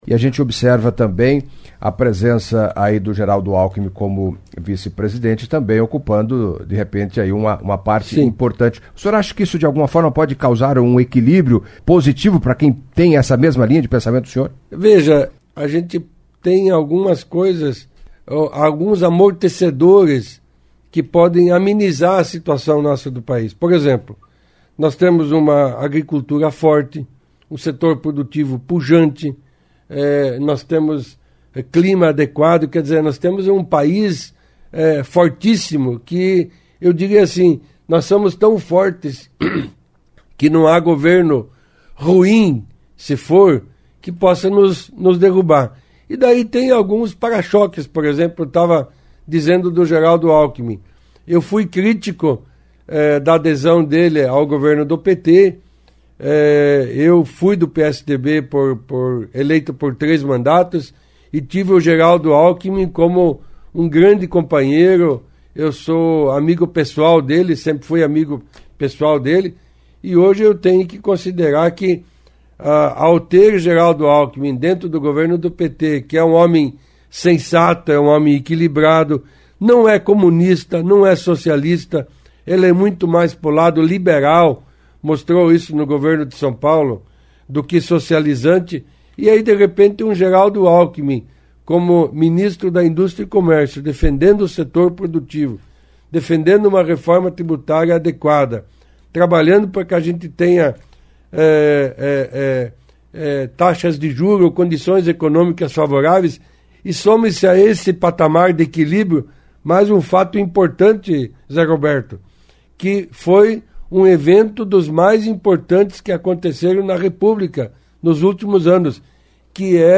Em entrevista à CBN Cascavel nesta quarta-feira (28) Alfredo Kaefer, que foi deputado federal por três mandatos e fez parte de importantes comissões na Câmara, debateu orçamento, aplicação de recursos, ajuste fiscal, entre outras discussões no âmbito nacional, demonstrou preocupação com os reflexos do governo Lula, a partir de janeiro.